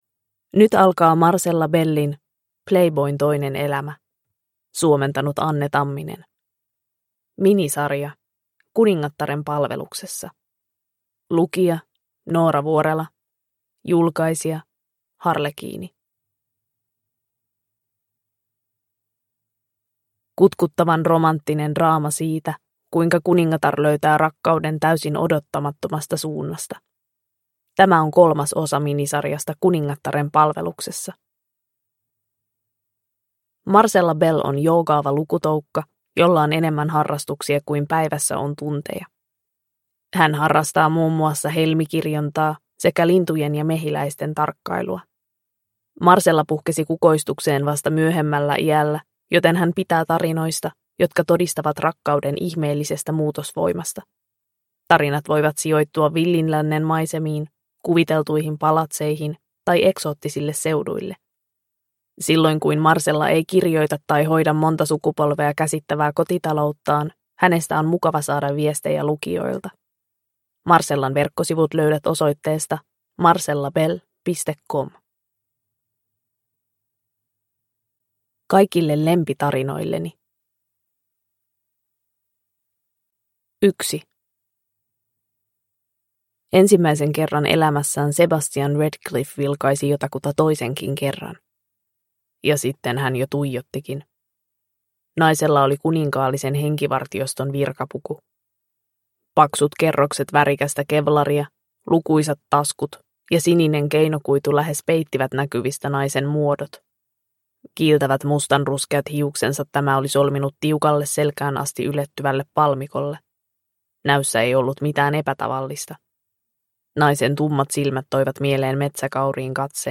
Playboyn toinen elämä (ljudbok) av Marcella Bell